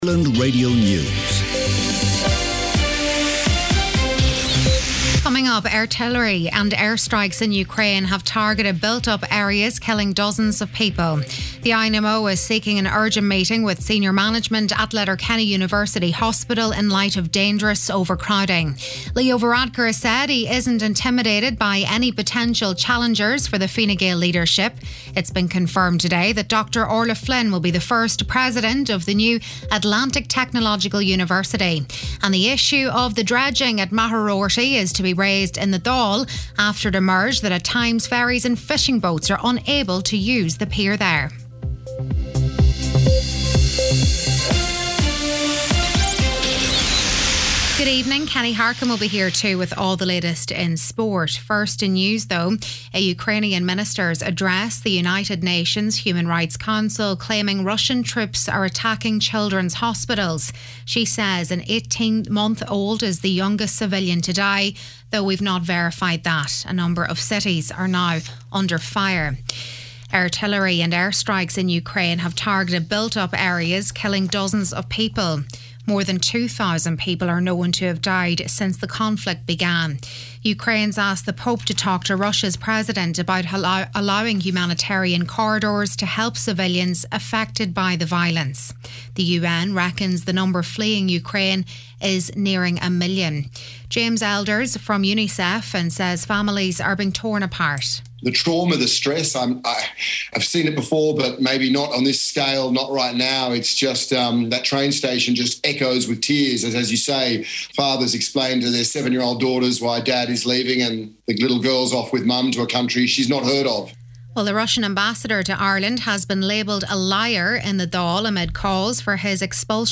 Listen back to main evening news, sport & obituaries